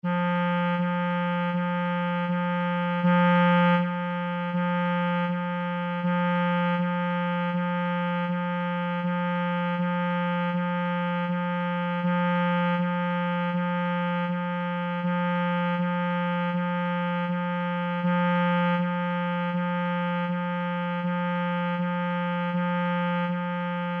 Velaquí atoparedes os arquivos de audio coas notas da escala musical:
Nota FA